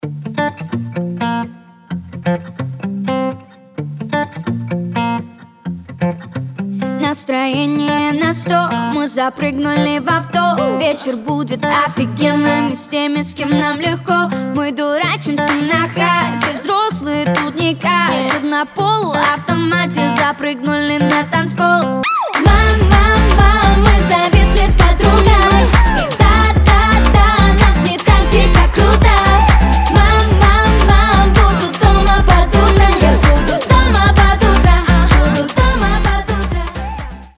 • Качество: 320, Stereo
гитара
позитивные
веселые
озорные
teen pop